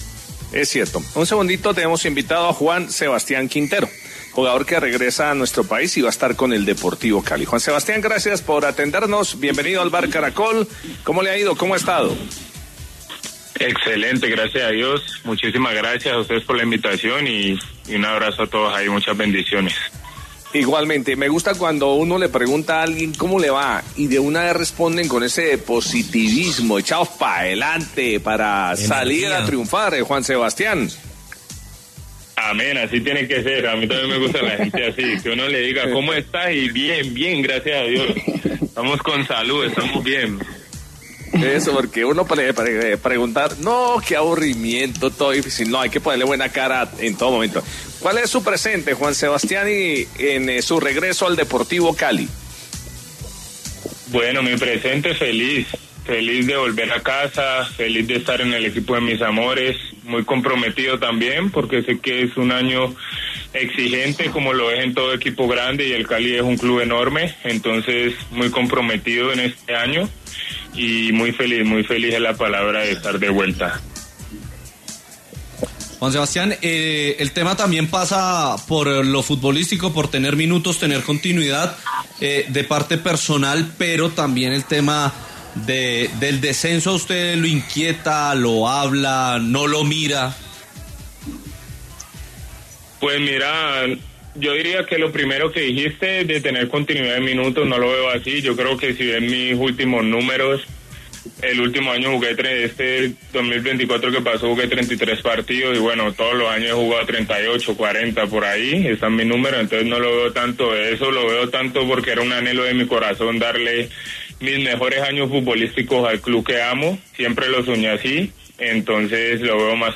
En entrevista con El VBar de Caracol Radio, el jugador afirmó su compromiso con el equipo y declaró que siempre ha sido el club de sus amores.